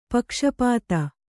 ♪ pakṣa pāta